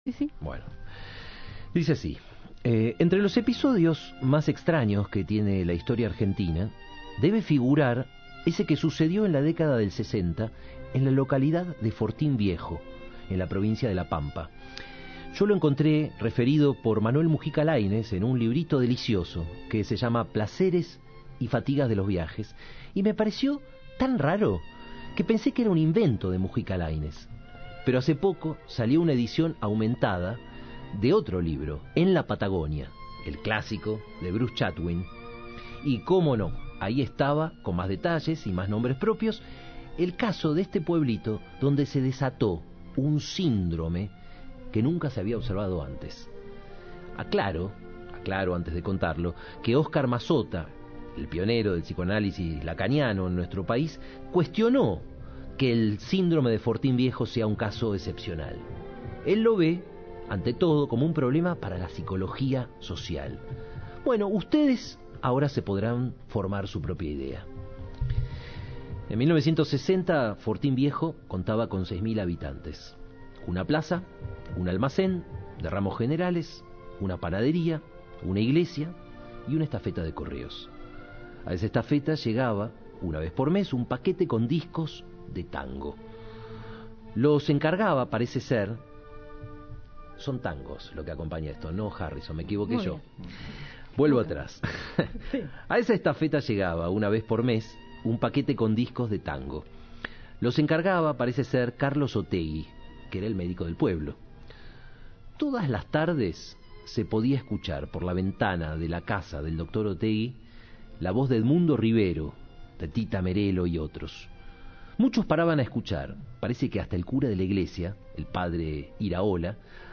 leyó en vivo uno de sus cuentos: El efecto ex